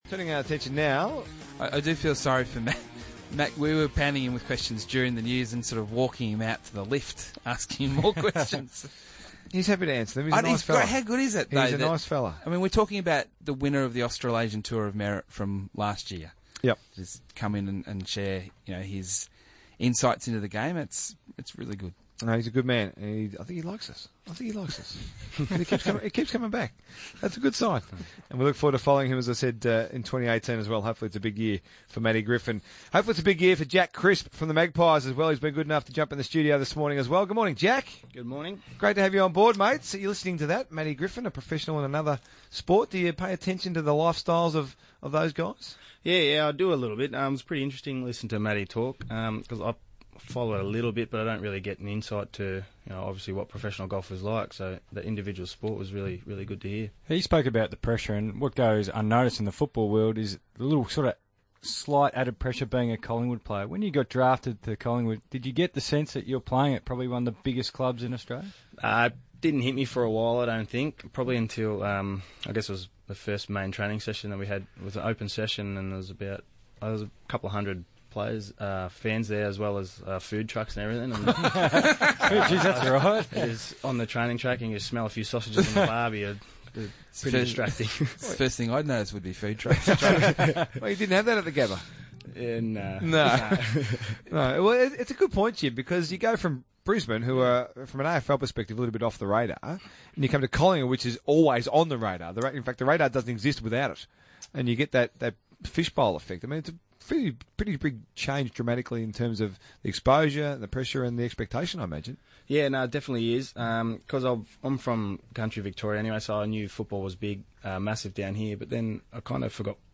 Listen in as midfielder Jack Crisp joins the RSN Breakfast team to provide an update on the Magpies' pre-season campaign.